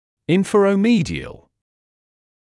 [ˌɪnfərə(u)’miːdɪəl][‘инфоро(у)’миːдиэл]нижне-медиальный; нижне-медиально